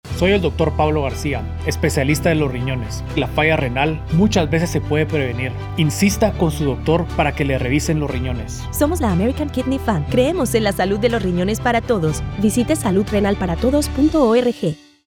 Broadcast-quality PSAs available at no cost to your station.